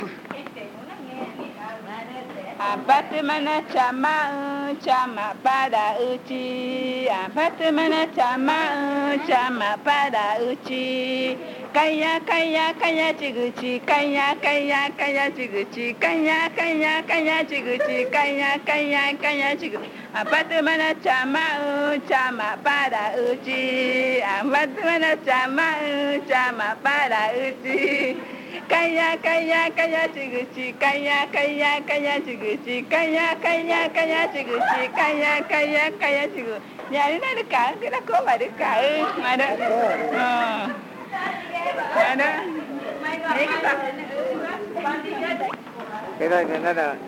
Cantos y juegos de niños
HC-BOYAHUASU-CAS001-A-007-canto.juego.mp3 (776.9 KB)
Comunidad Indígena Boyahuasú
La canción fue grabada en casete el 19 de enero del año 2002.
The song was recorded on cassette on January 19, 2002.